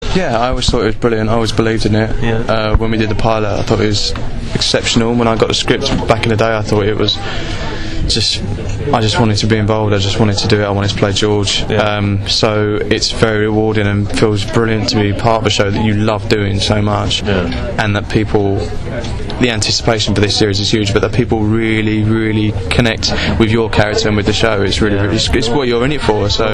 In the hours before the preview screening of episode one, I interviewed Russell (werewolf George), Aidan Turner (vampire Mitchell) and Lenora Crichlow (ghost Annie).